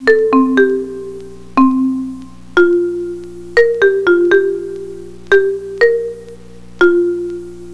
We also can make random rhythms using a duration chosen by ALEATORIC: